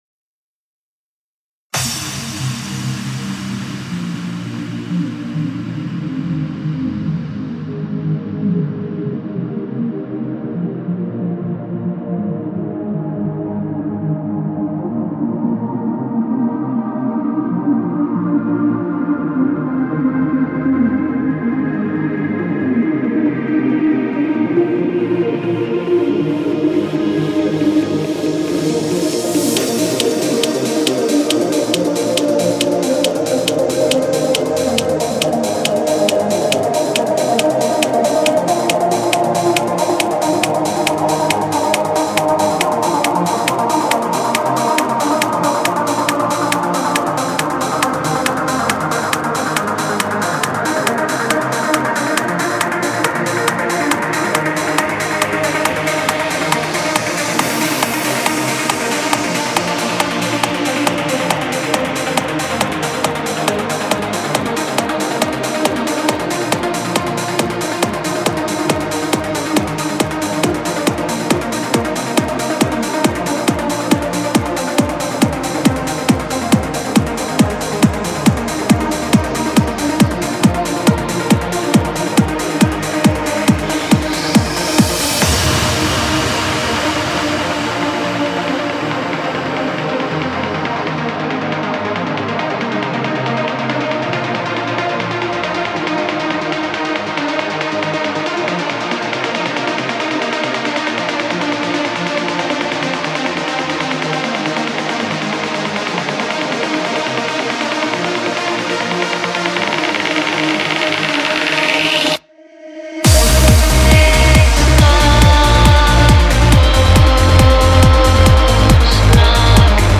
File Size: 20.9MB　Length: 8:40 minutes　bpm:138
イントロ部分を少し長めに、３部構成でアレンジ・ミックスしたメロディックなトランス（Trance）です。
女性ボーカルの音源を使用してメロディを入れています。